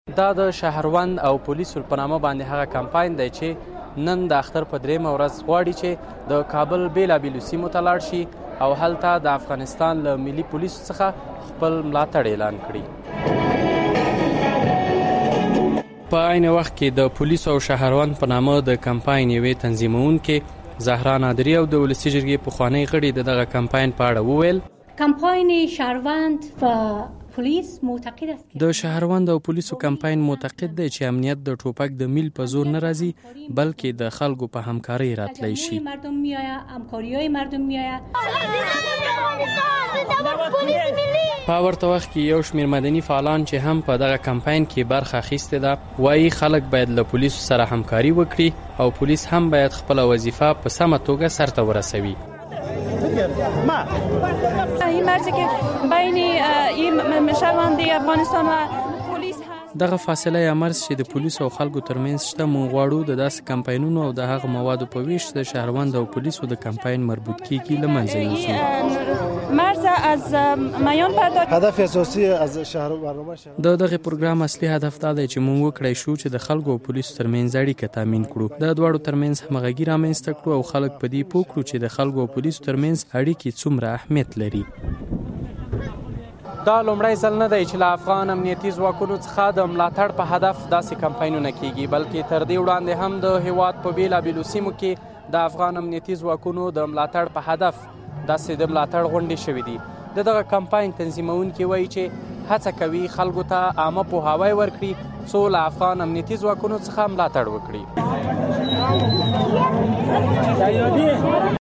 راپور له دې برخې دی.